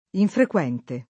infrequente [ infrek U$ nte ] agg.